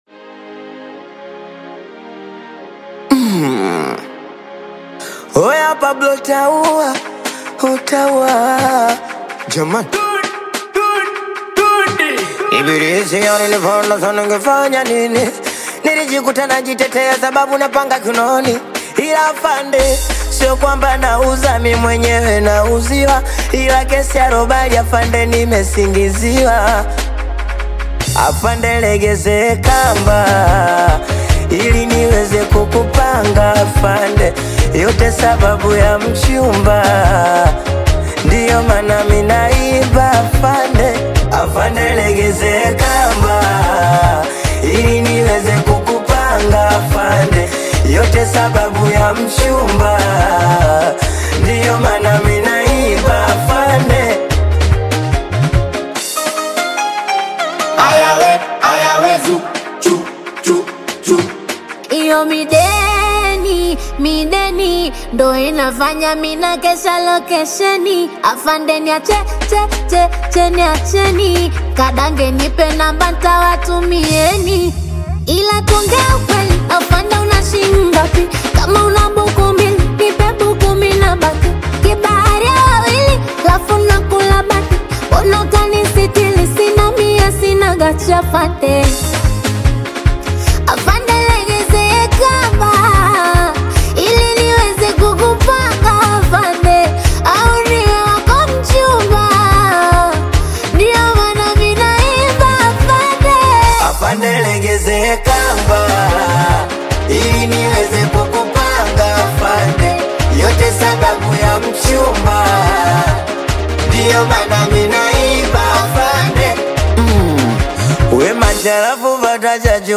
Genre: Singeli